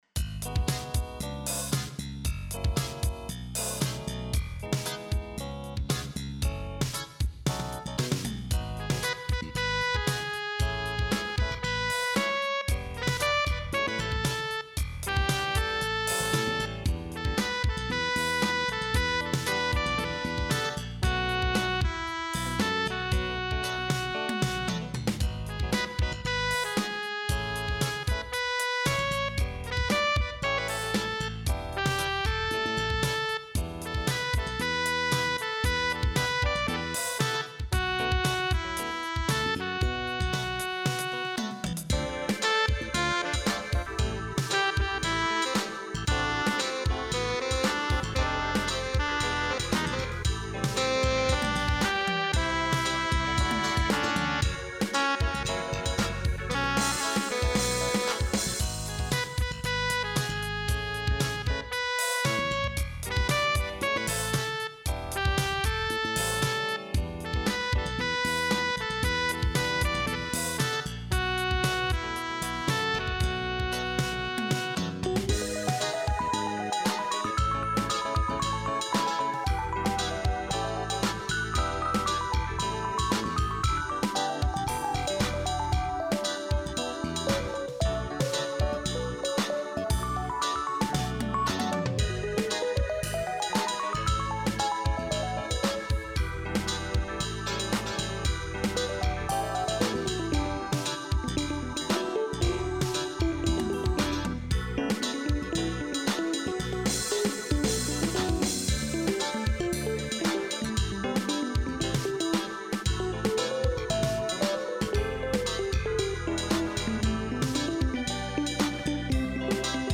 フリーＢＧＭ フュージョン
いろいろ試した挙句、「スムーズジャズ　Grover テナーサックス」
果たして、上手く、ブレッカー・ブラザース風のフュージョンサウンドに仕上がってるでしょうか。